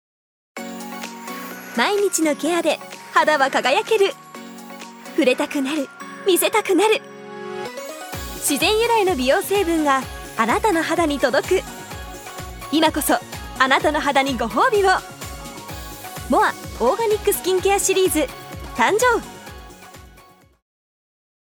女性タレント
ナレーション４